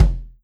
S_kick2_8.wav